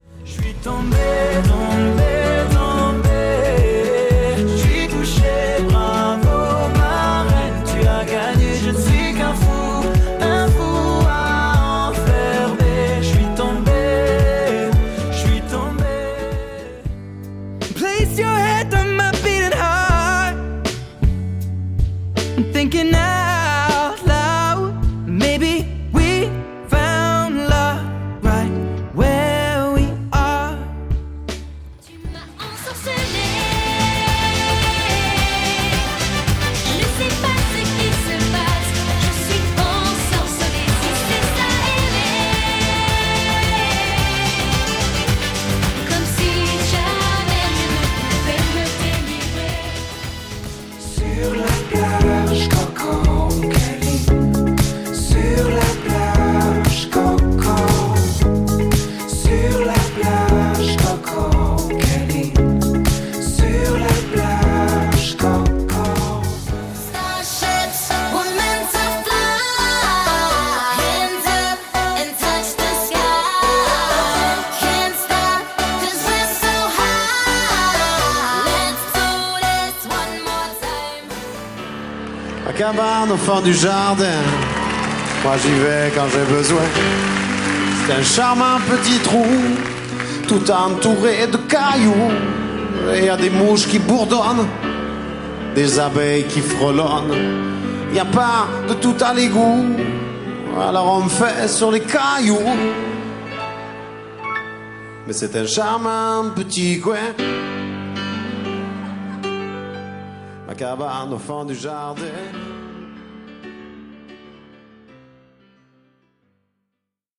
petite relance en musique.